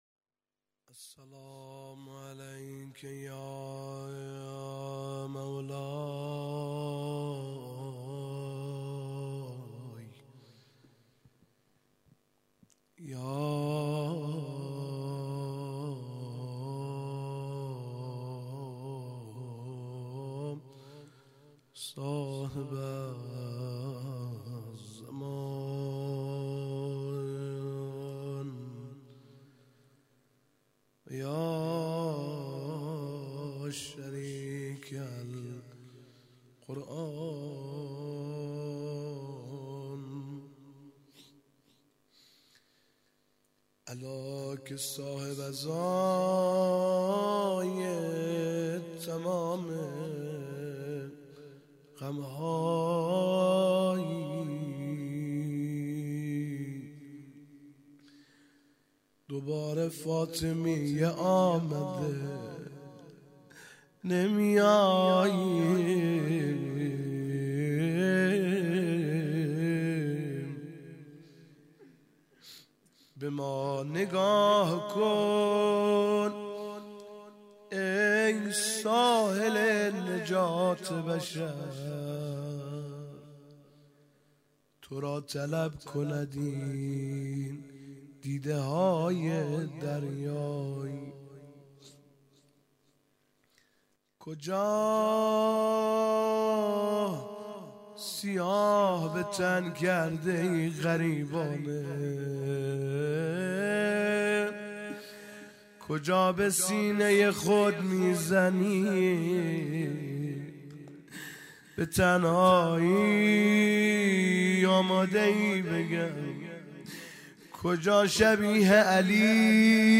روضه
هیئت دانشجویی فاطمیون دانشگاه یزد